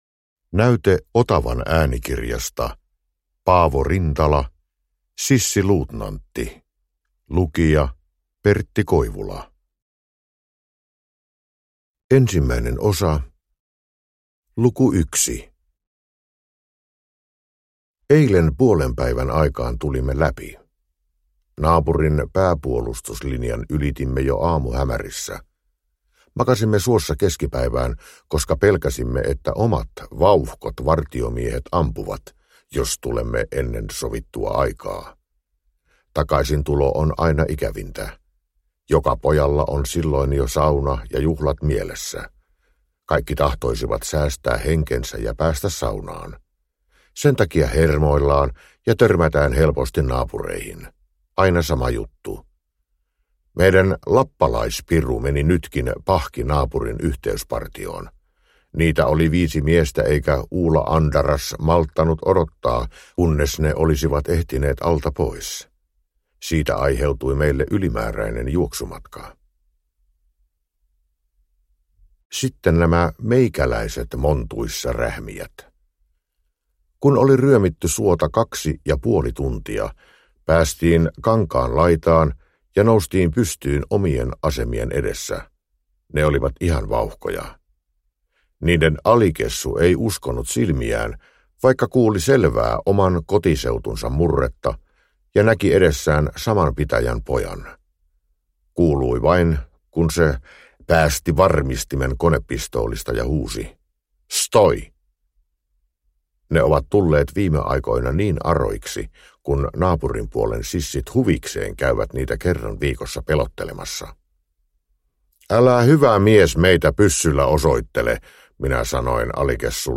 Sissiluutnantti – Ljudbok – Laddas ner
Uppläsare: Pertti Koivula